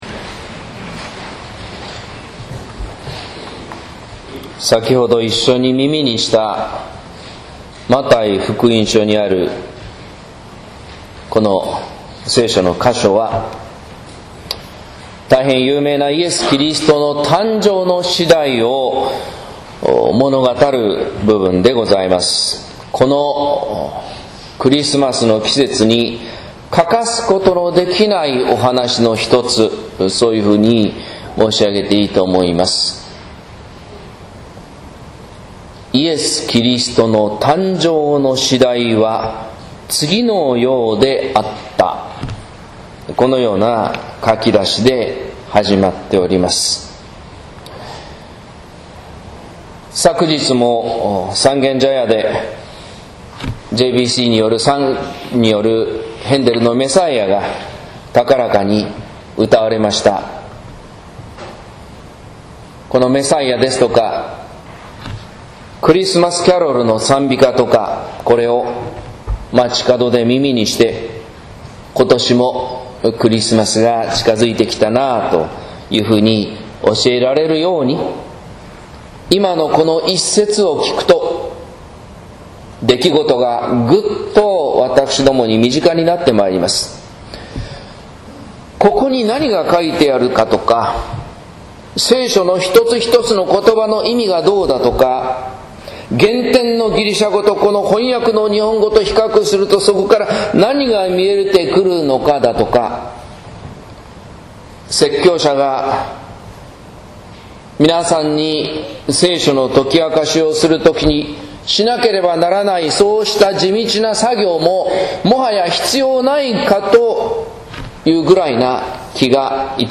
説教「神の正しさ『インマヌエル』」（音声版）